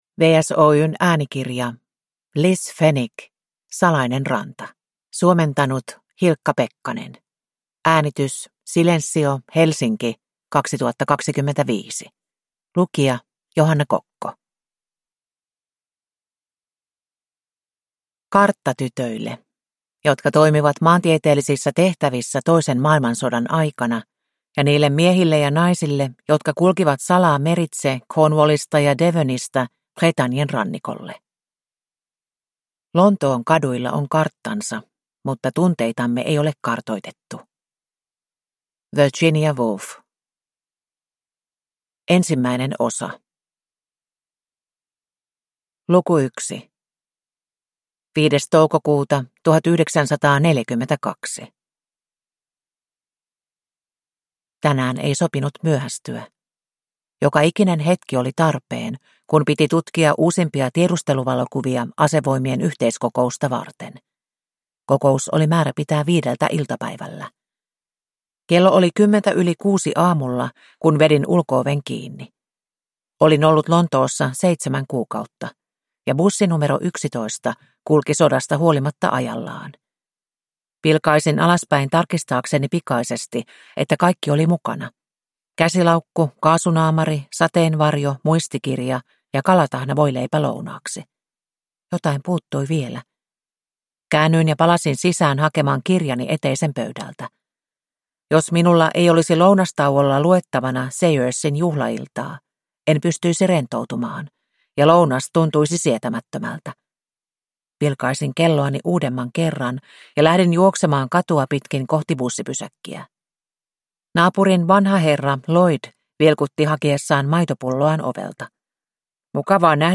Salainen ranta – Ljudbok